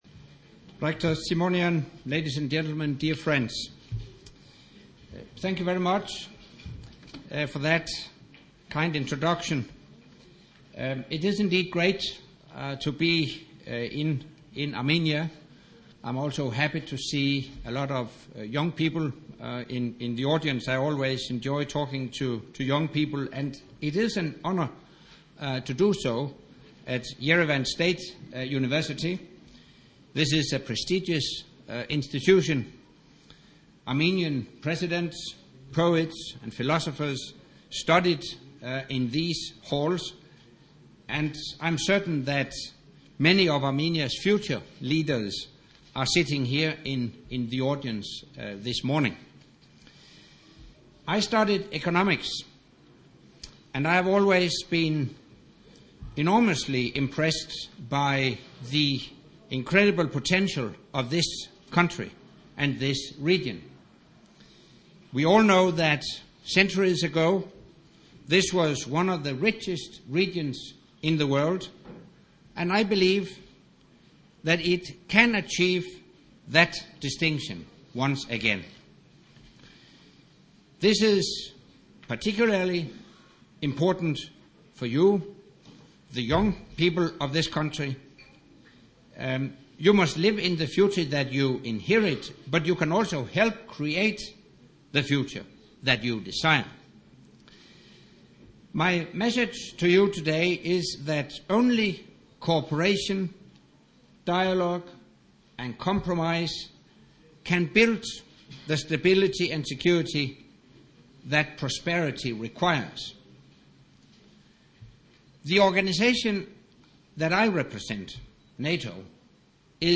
Speech
by NATO Secretary General Anders Fogh Rasmussen at the Yerevan State University in Yerevan, Armenia